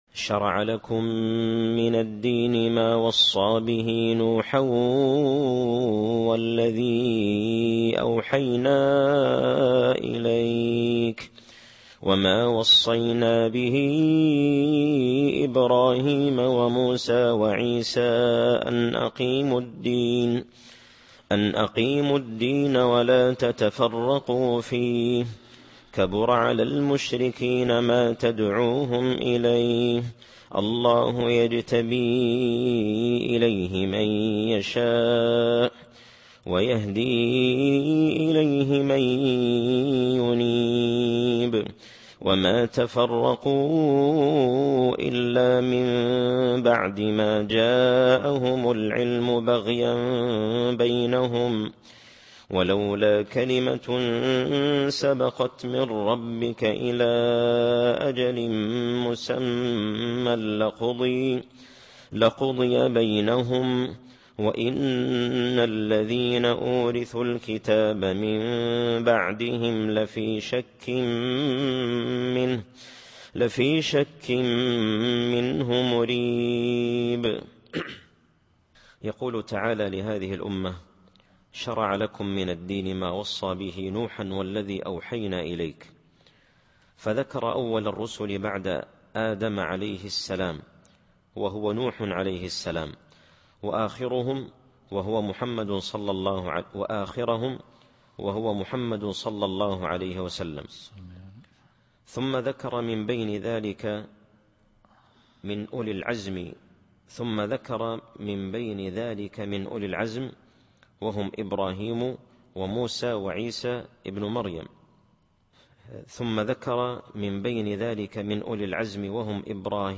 التفسير الصوتي [الشورى / 14]